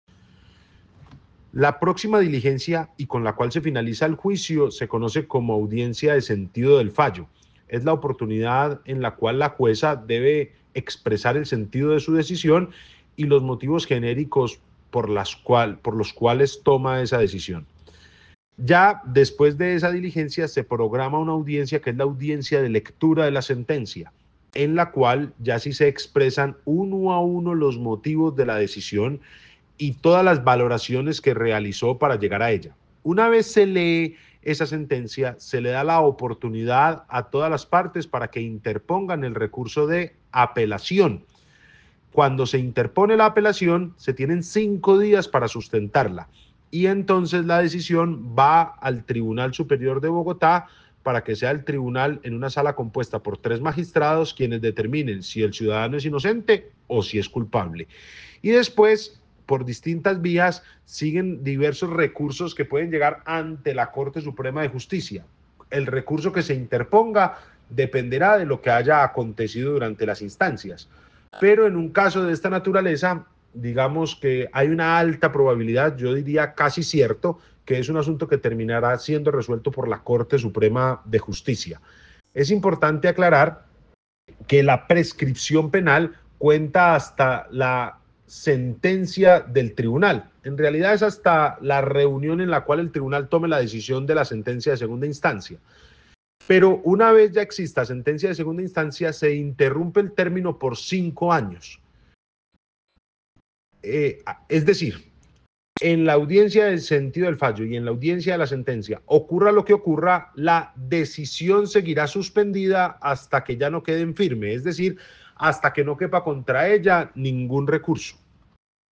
abogado penal